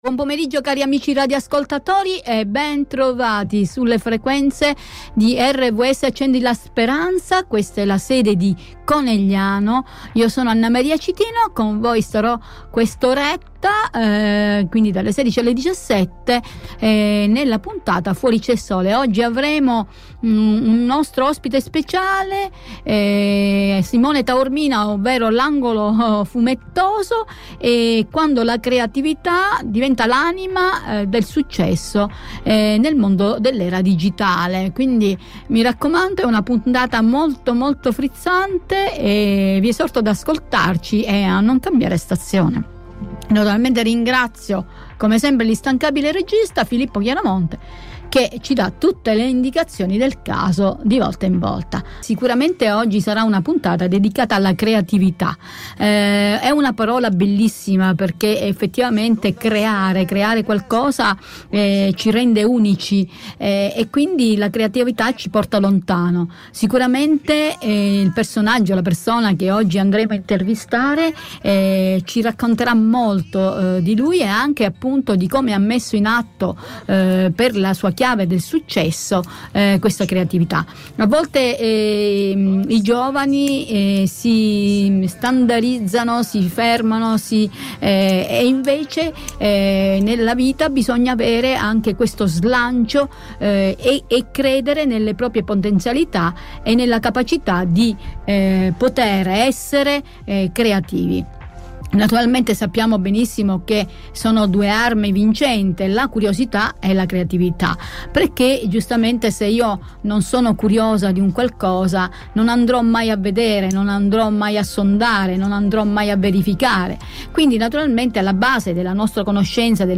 In questa puntata abbiamo avuto il piacere di intervistare
in collegamento da Catania